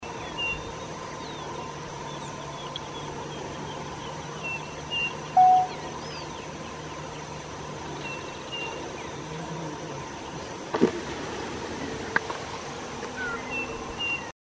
Striped Cuckoo (Tapera naevia)
Life Stage: Adult
Location or protected area: Distrito Guadalupe Norte - Zona Jaaukanigás
Condition: Wild
Certainty: Recorded vocal